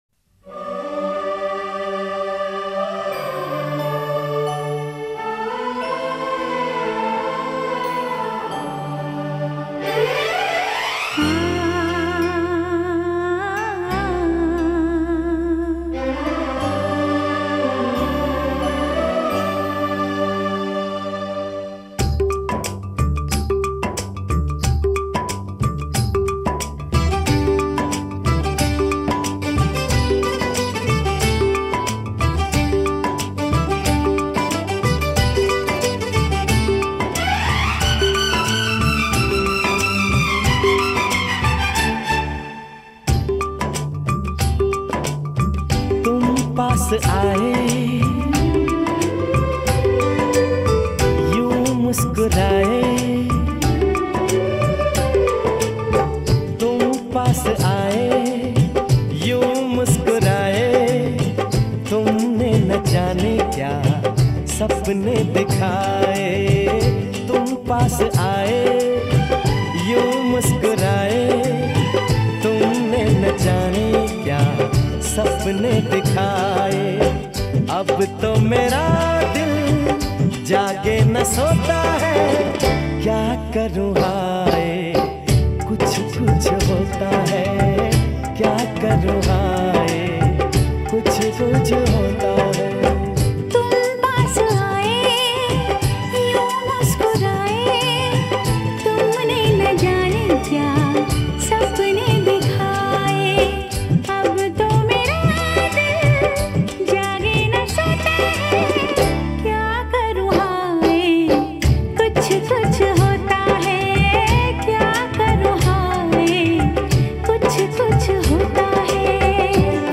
Theme song for a popular Bollywood movie (1998)